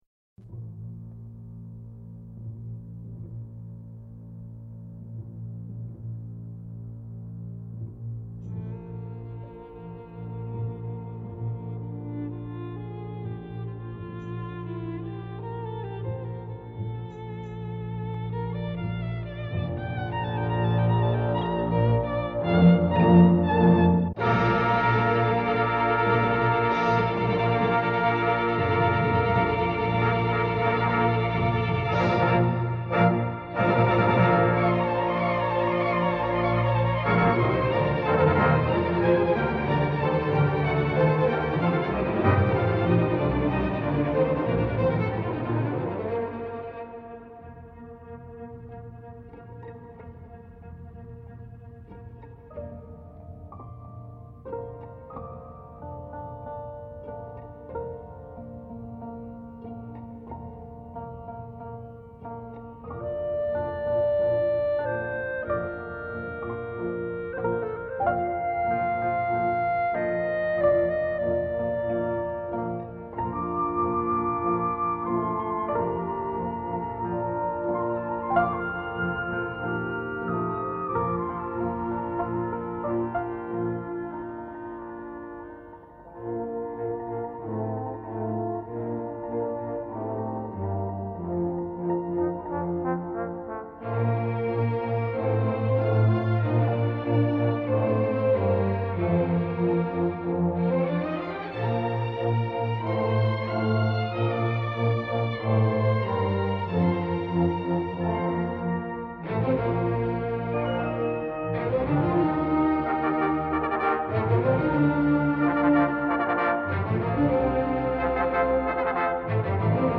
در دستگاه «همایون» و در مایه‌ «شوشتری»